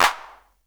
DrClap15.wav